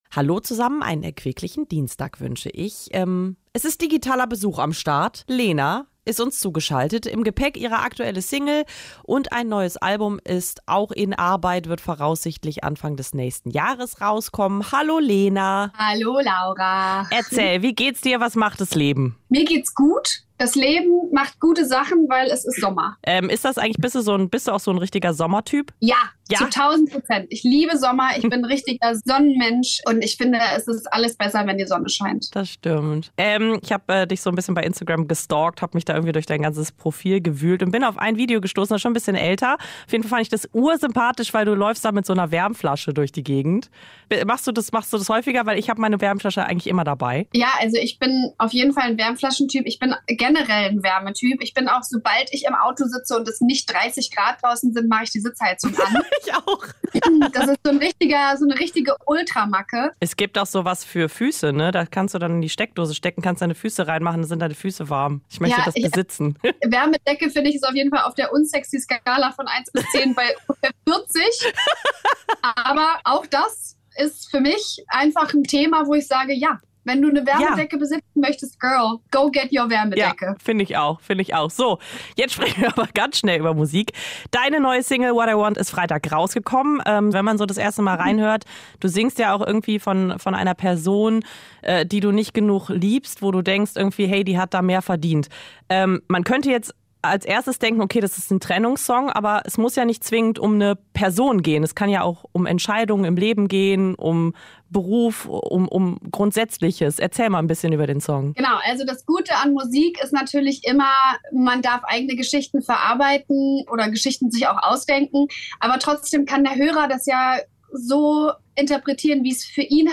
ph230620 Das Interview mit Lena › RADIO NRW